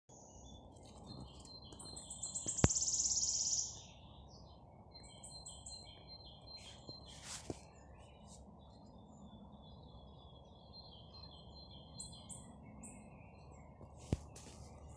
пеночка-рещетка, Phylloscopus sibilatrix
Ziņotāja saglabāts vietas nosaukumsLantes muiža, Ropažu nov.
СтатусПоёт
Примечанияmuižas parka malā, kas robežojas pie nogāzes uz upes malu